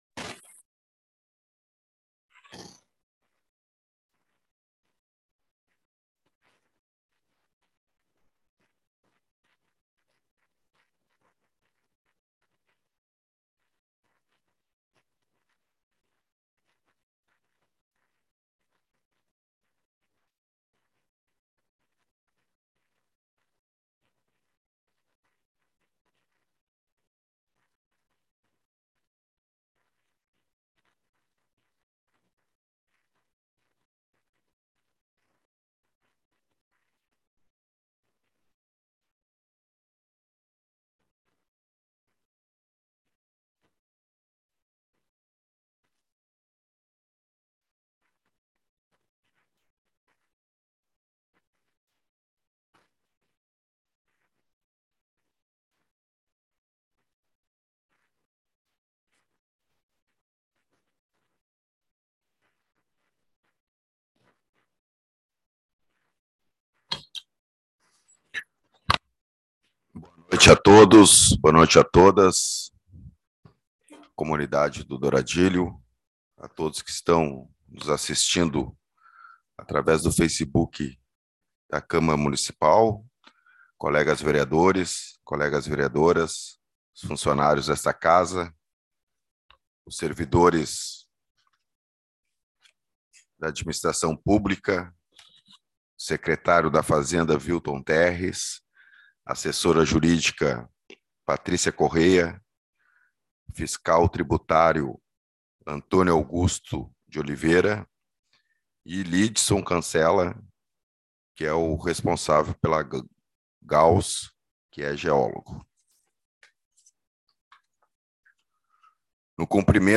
Publicação: 02/06/2022 às 10:25 Abertura: 02/06/2022 às 10:25 Ano base: 2022 Número: Palavras-chave: Apliação Perímetro Urbano Anexos da publicação Áudio da Audiência Pública Douradilho 02/06/2022 10:25 Compartilhar essa página...